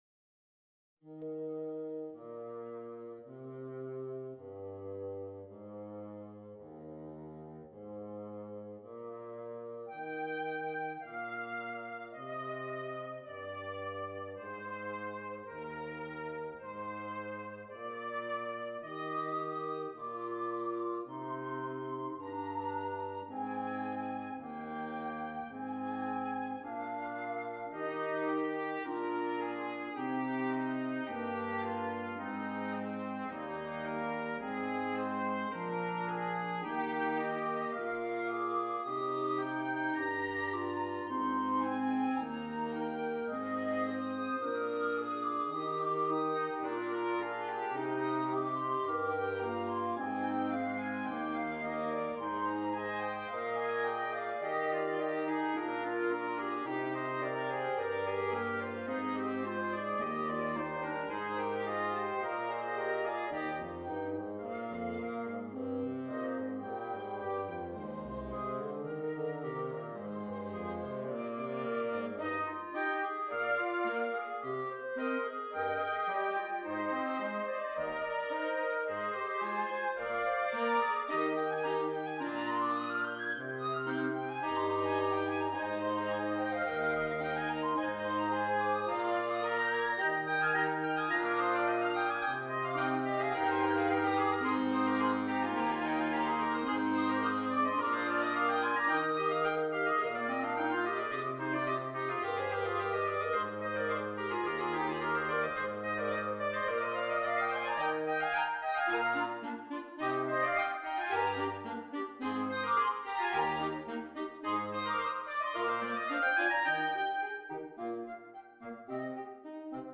Interchangeable Woodwind Ensemble